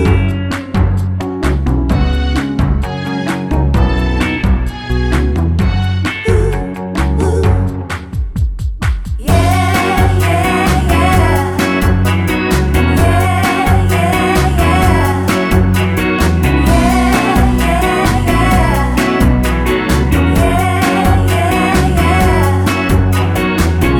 One Semitone Down Pop (2000s) 3:42 Buy £1.50